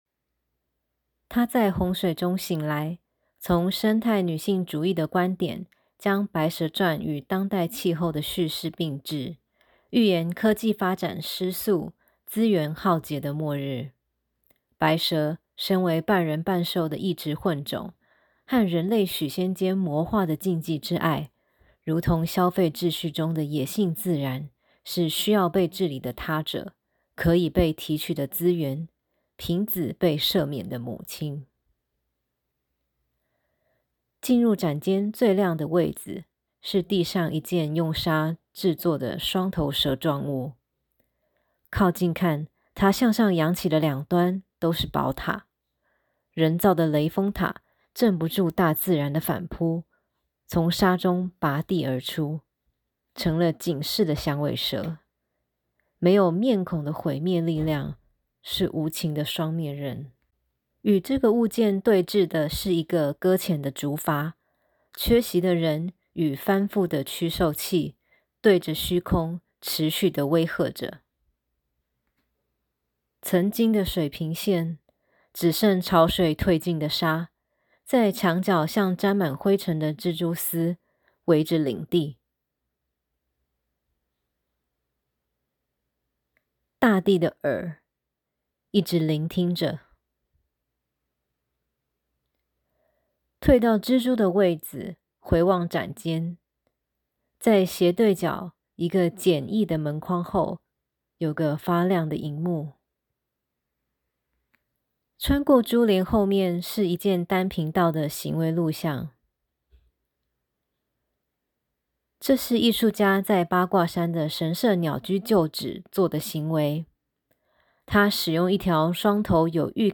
語音導覽.mp3